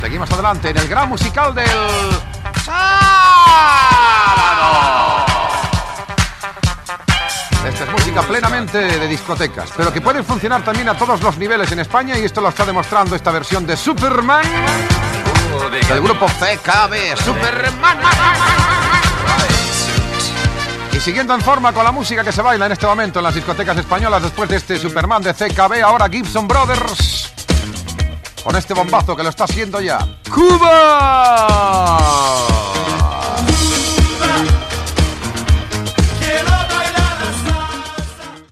Identificacio del programa i presentació de dos temes musicals
Musical